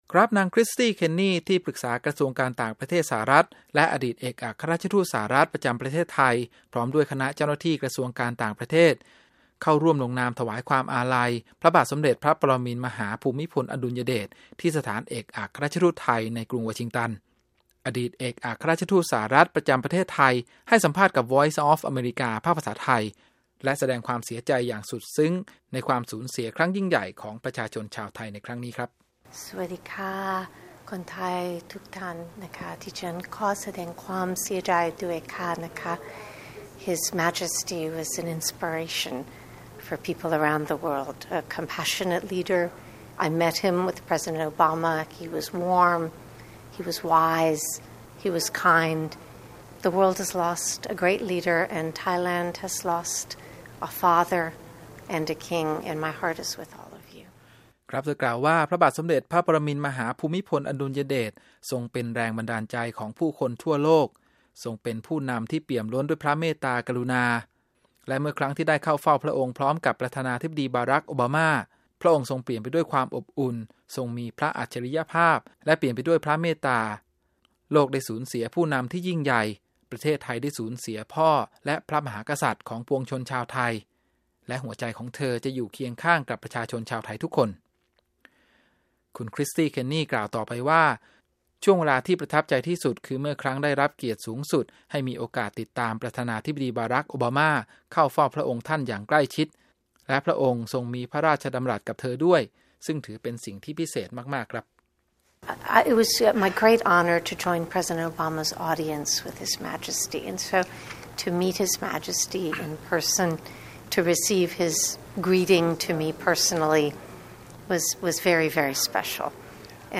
Interview Kristie King Tributes